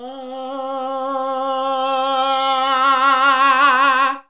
AAAAH 2 C.wav